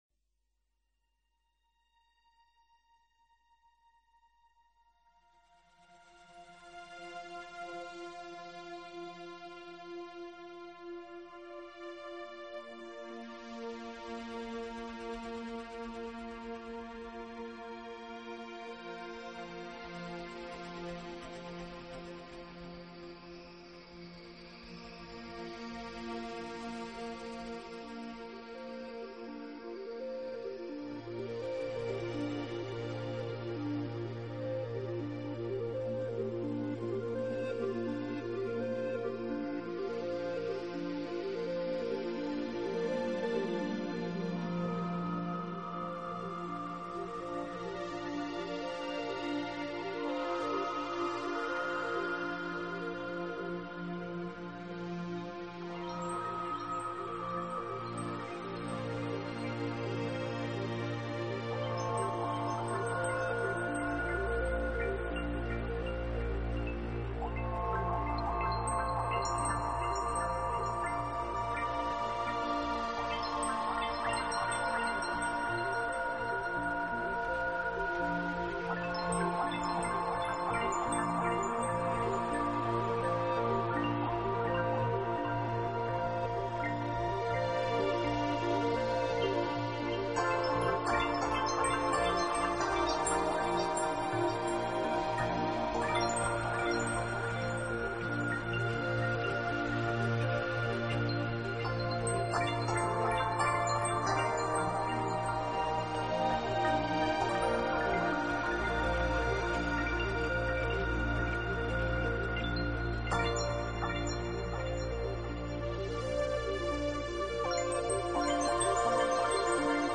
专辑语言：纯音乐
这种音乐是私密的，轻柔的，充满庄严感并总是令人心胸开阔。